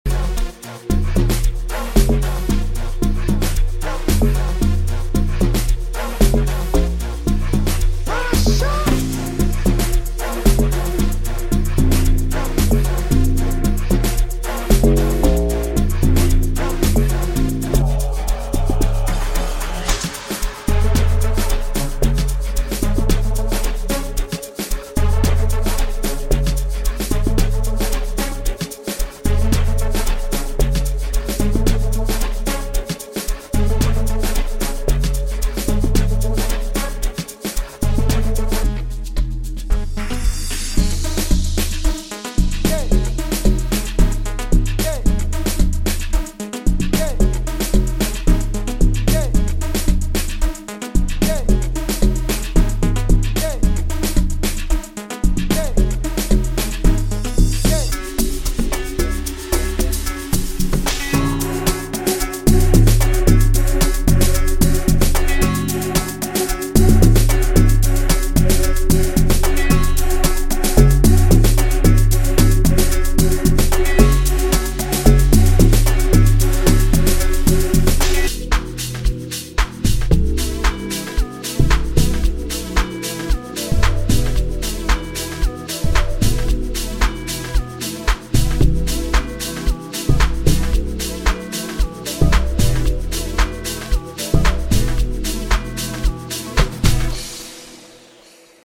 • 65 Drum Loops
• 30 Melodic Loops
• 7 Bass Loops